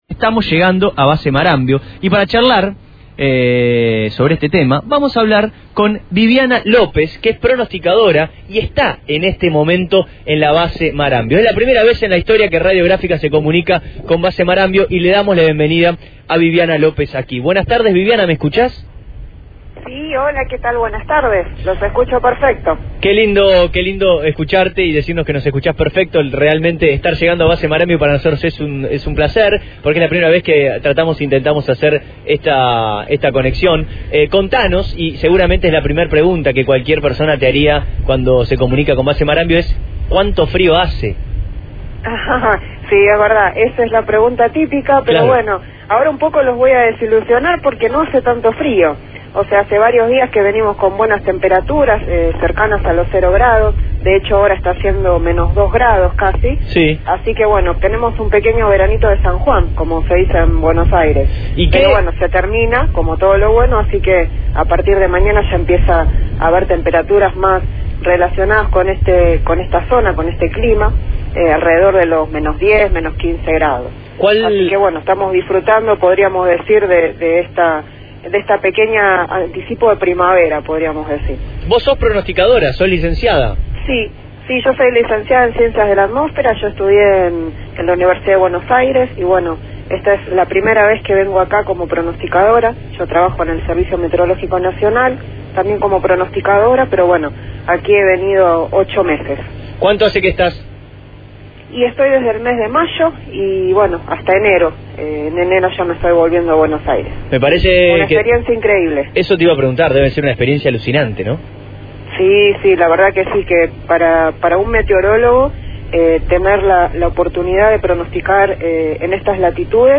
Los 95 años de la radiofonía argentina se vivieron de una manera especial en Abramos La Boca. Pues no sólo se hizo el programa desde la terraza, sino que también se estableció comunicación con Base Marambio, estación científica y militar ubicada en la Antártida.